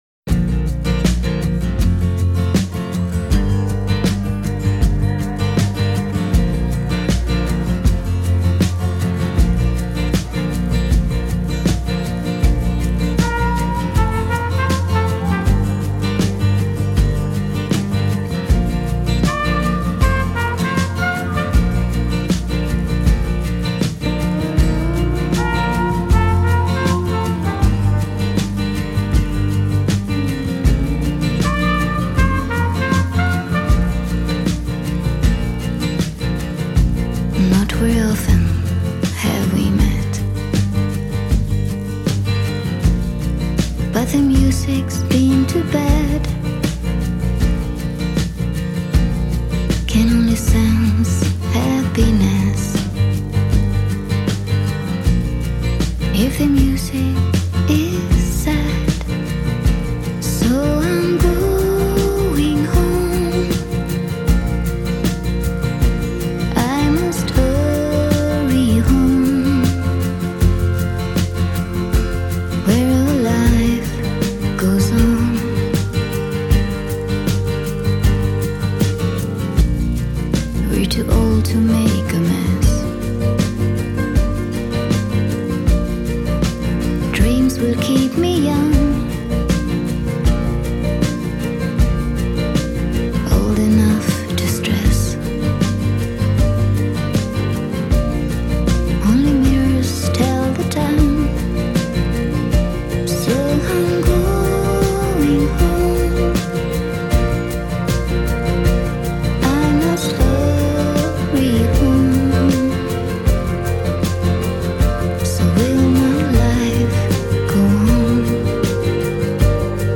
★ 種類涵蓋爵士、古典、流行、民謠等不同曲風，以最優異的設備、最發燒的手法精心錄製！